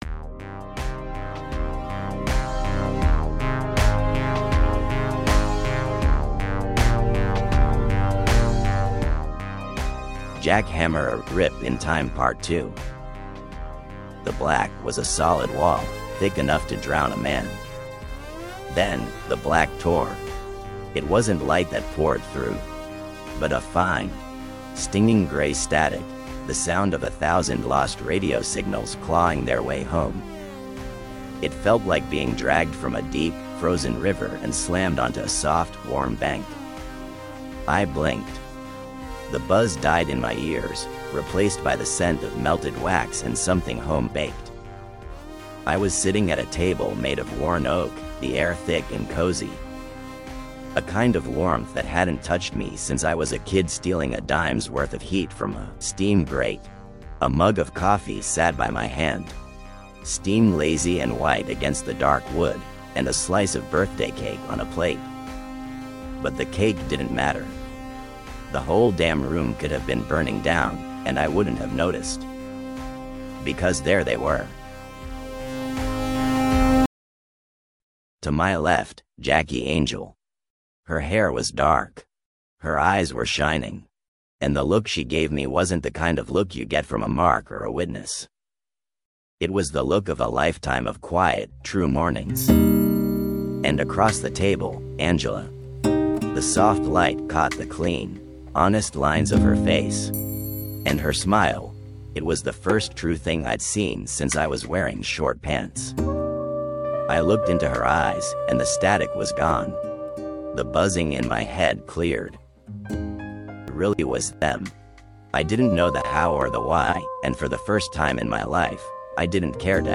Jack Hammer-A Rip in Time Part 2: December’s Birthday - Enhanced Audio Book - Newz Hammer
Full noir audio drama playlist featuring December's Debt and the complete saga.